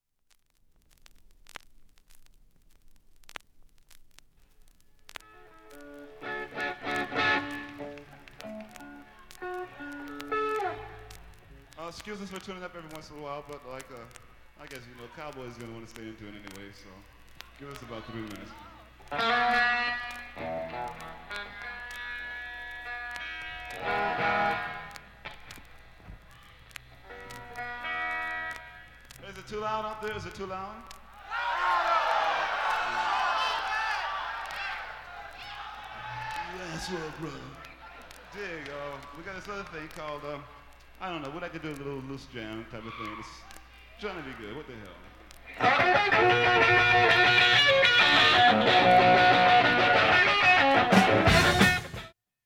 下記以外はサーフェス音などはありません。
音質良好全曲試聴済み。
かすかなチリプツ、シャリ音出ます。
ほか５回までのかすかなプツが１箇所
ワイト島フェスティヴァルまでのライヴ音源